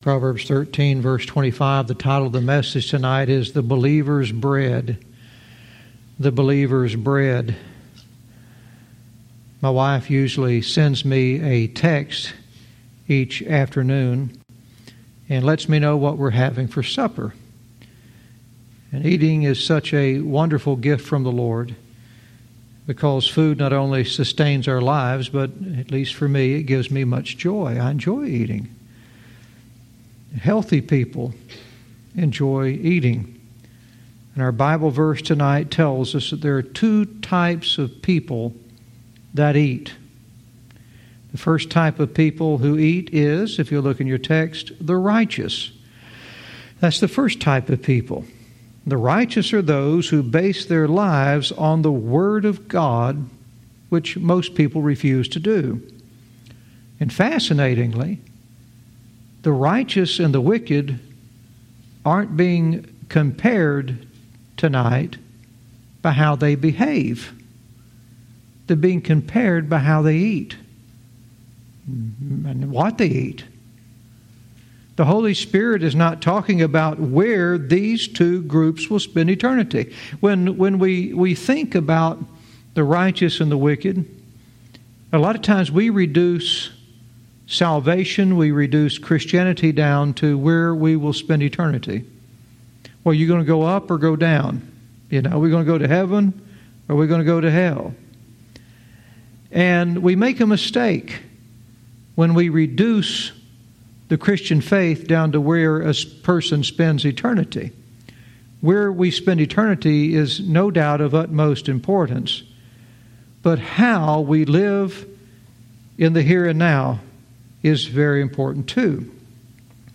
Verse by verse teaching - Proverbs 13:25 "The Believer's Bread"